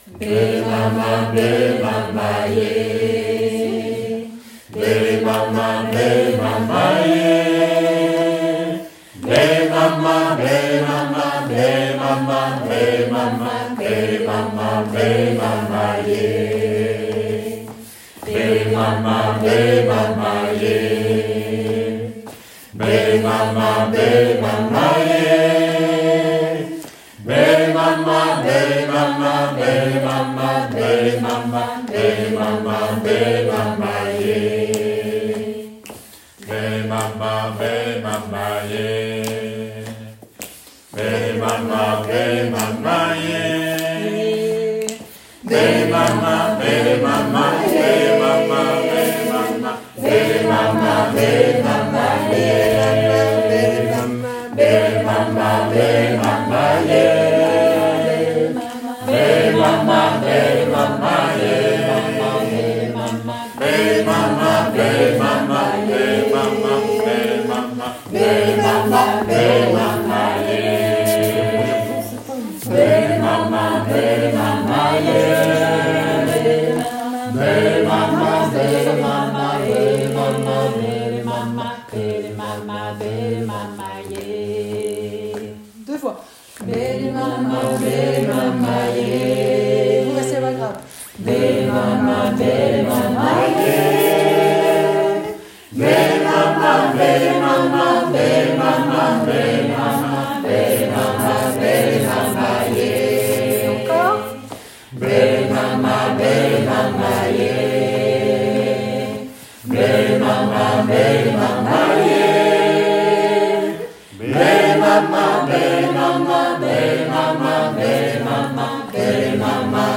- Unisson (voix grave tous)                    x2
- Canon de voix grave tous                    x2
- Canon avec 4 voix                                 x2